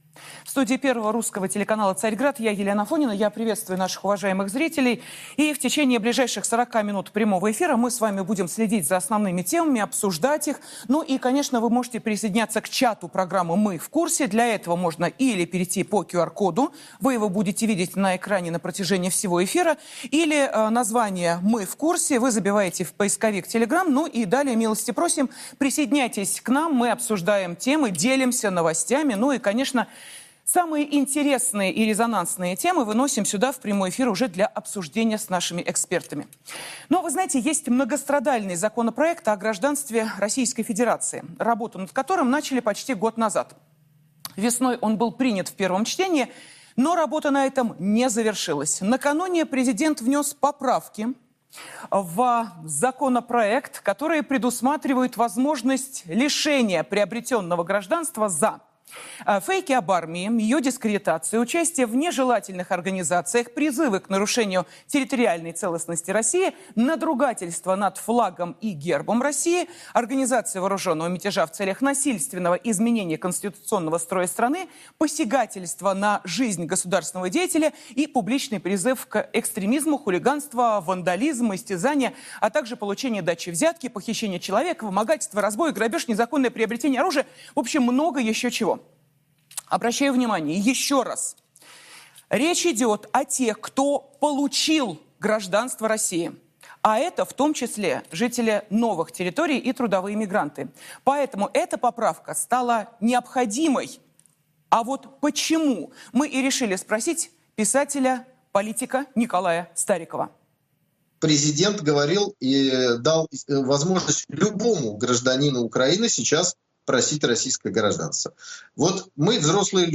В эфире «Царьграда» обсудили президентские поправки к закону, в целом ряде случаев дающие возможность лишать приобретённого гражданства.